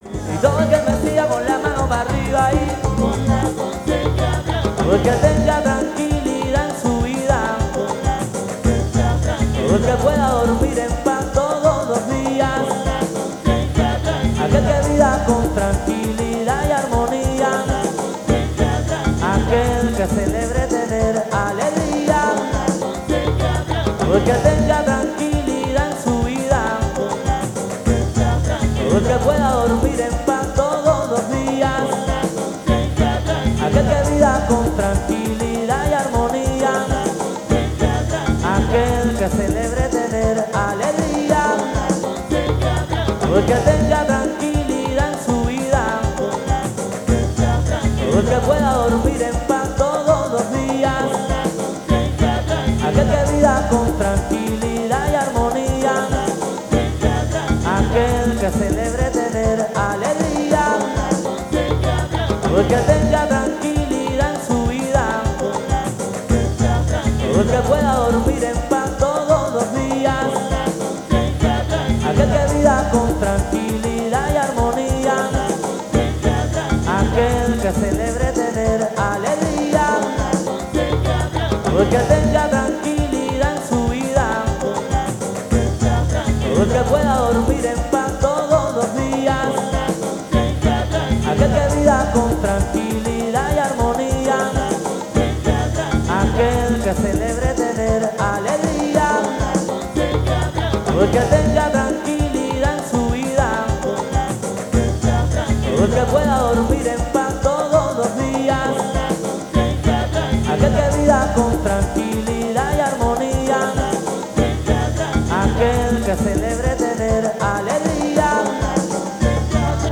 songo con efectos gear